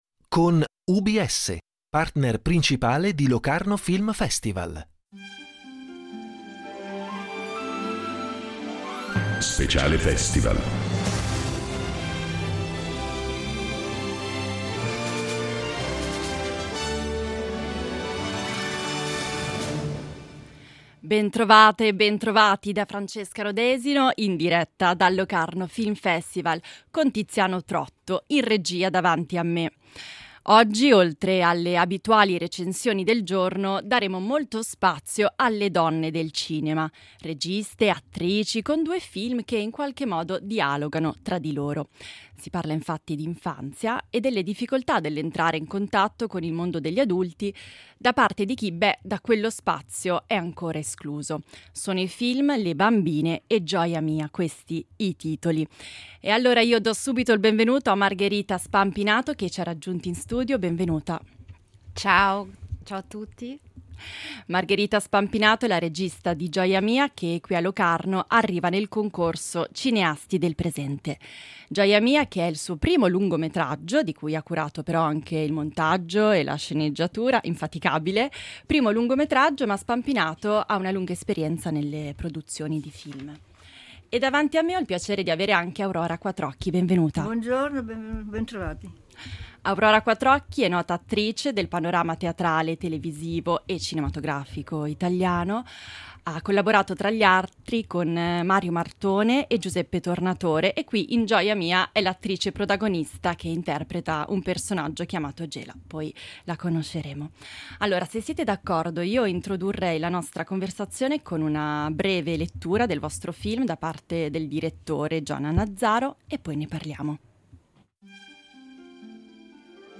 In diretta dal Locarno Film Festival